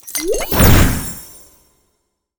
potions_mixing_alchemy_02.wav